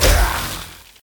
sceleton_hit1.ogg